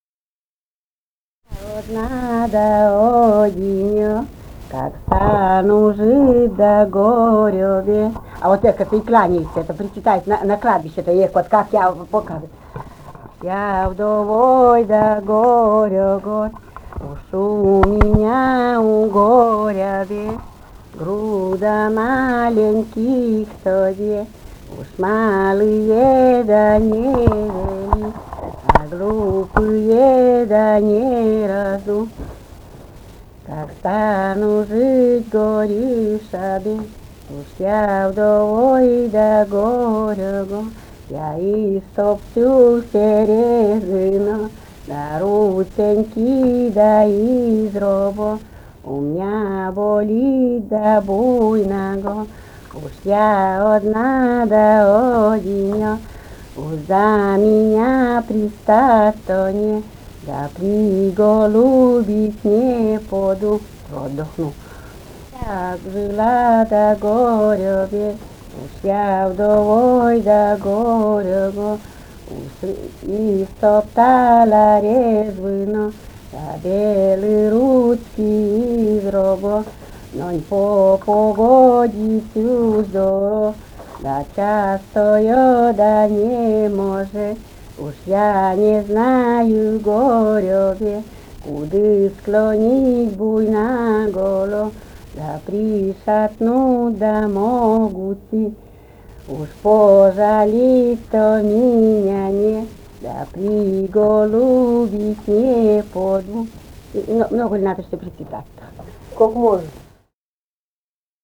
Живые голоса прошлого [[Описание файла::010. «Я одна да одинё[шенька]» (причитание по мужу).